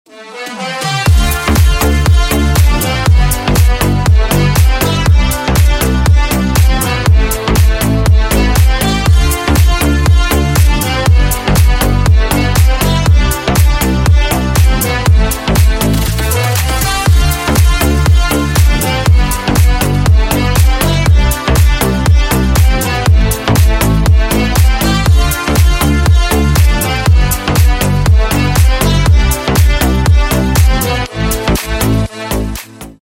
Клубные Рингтоны » # Рингтоны Без Слов
Танцевальные Рингтоны